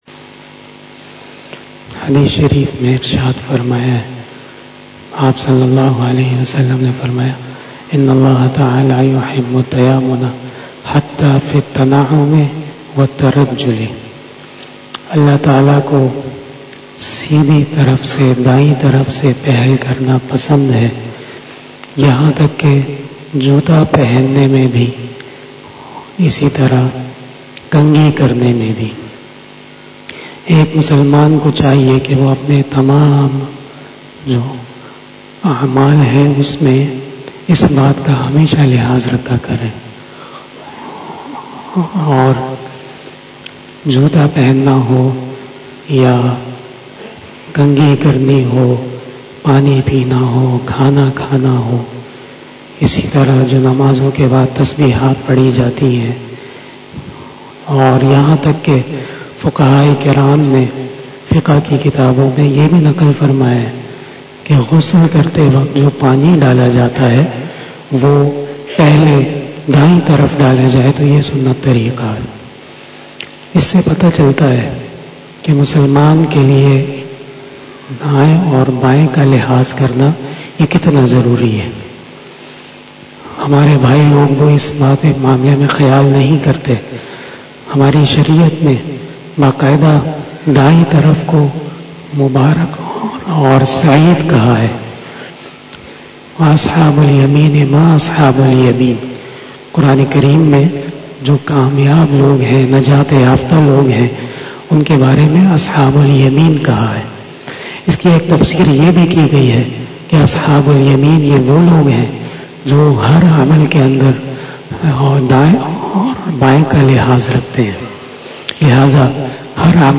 005 After Asar Namaz Bayan 16 January 2021 ( 02 Jamadil Us Sani 1442HJ) Saturday